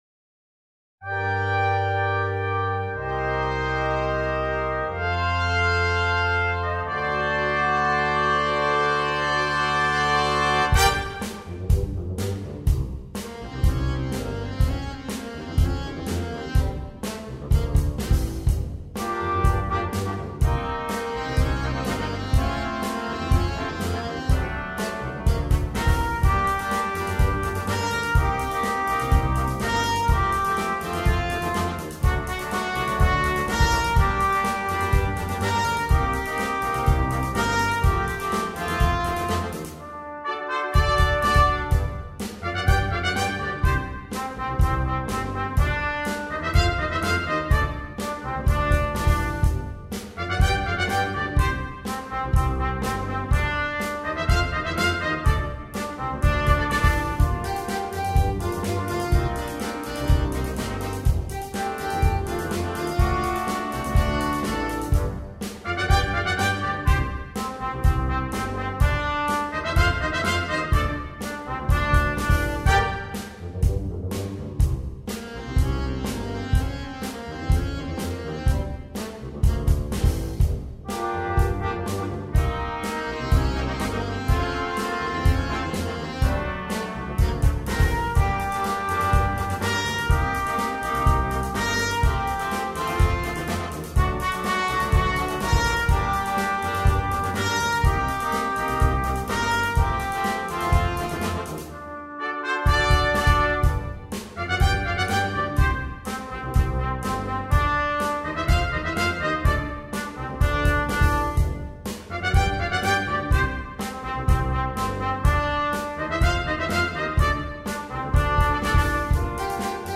для брасс-бэнда